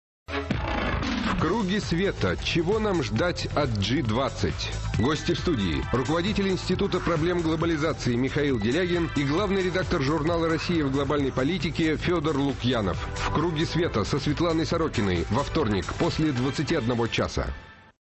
на радио «Эхо Москвы»
Аудио: анонс –